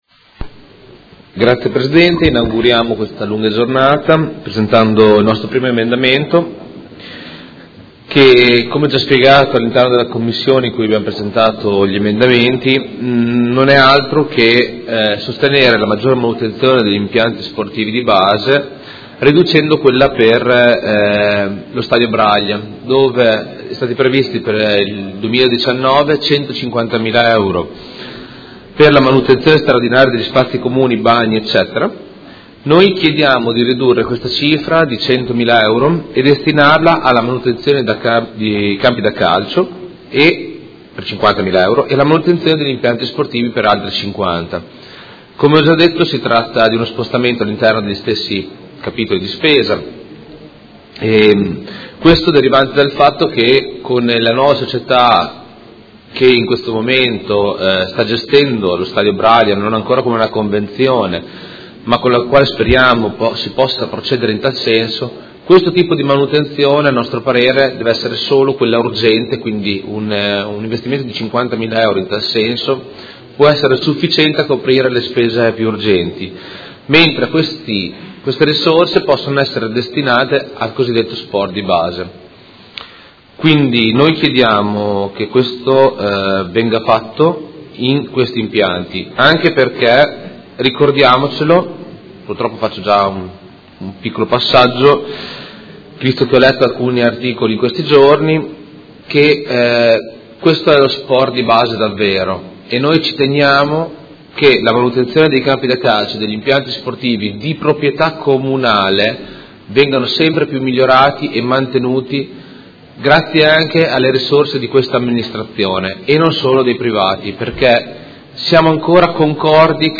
Seduta del 20/12/2018. Presenta emendamento Prot. Gen. 196253